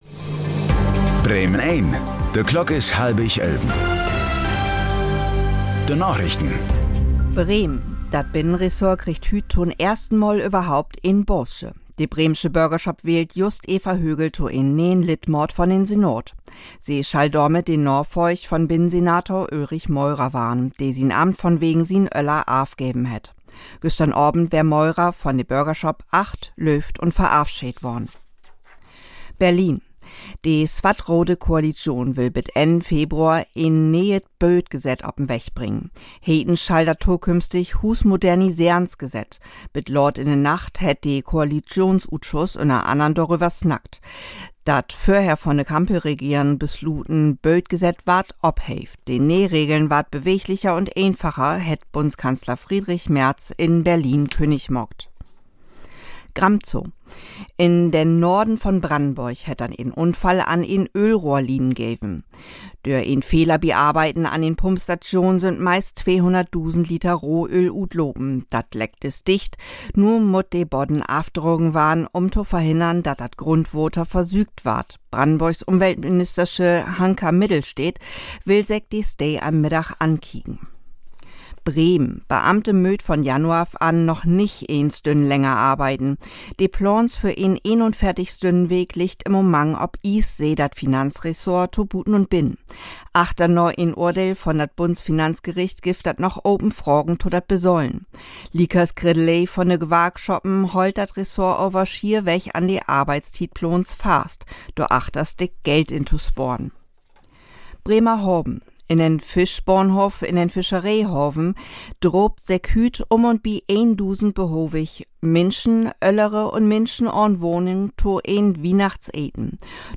Plattdüütsche Narichten vun'n 11. Dezember 2025